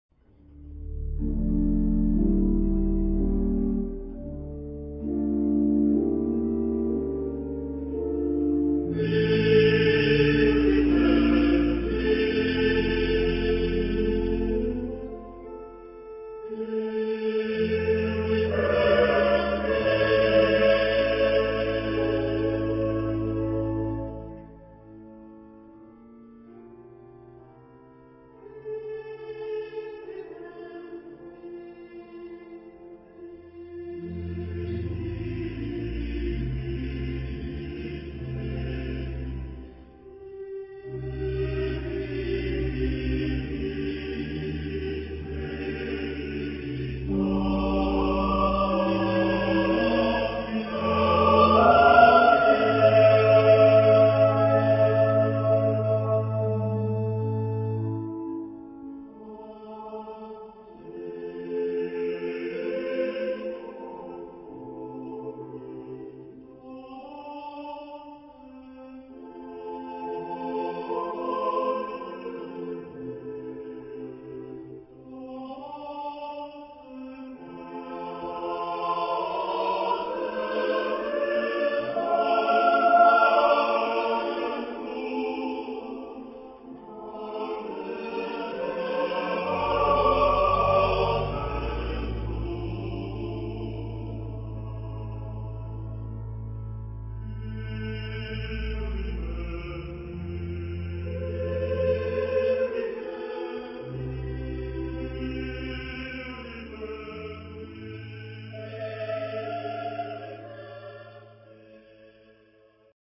Epoque: 19th century
Genre-Style-Form: Motet ; Sacred ; Psalm
Type of Choir: SATB  (4 mixed voices )
Instruments: Organ (1)
Tonality: D minor
sung by Kammerchor Stuttgart conducted by Frieder Bernius